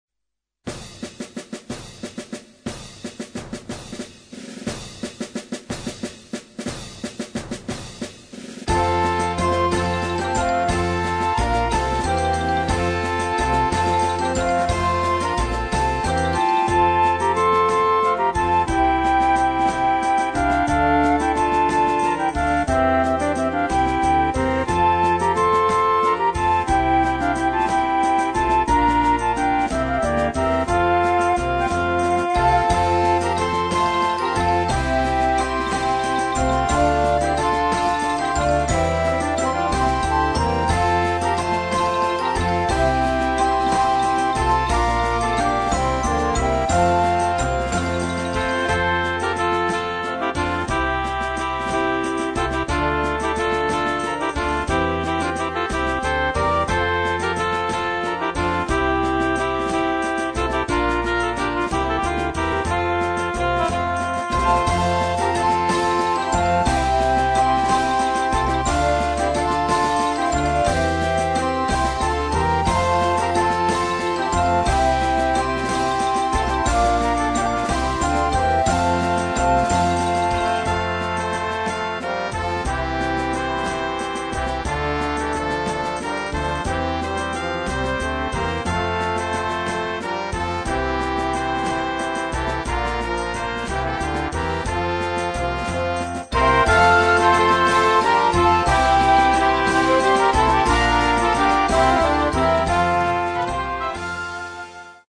für Jugendblasorchester Schwierigkeit
1:50 Minuten Besetzung: Blasorchester PDF